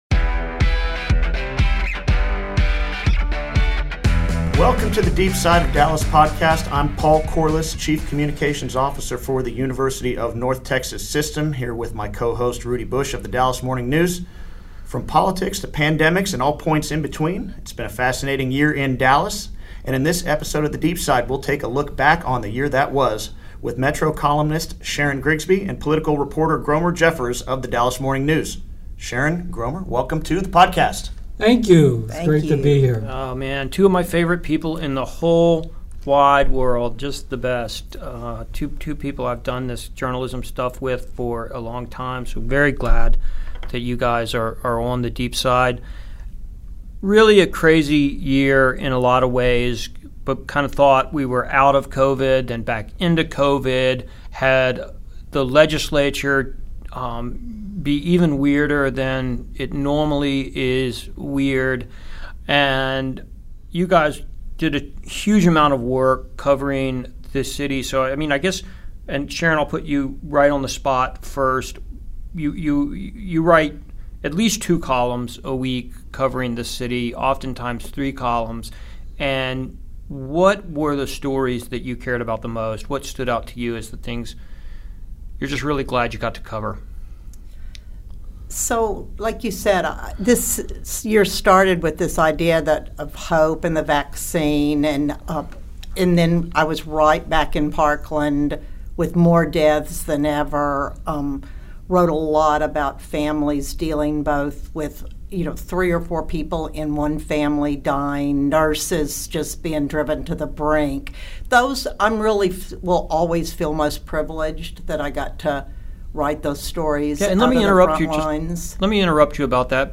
roundtable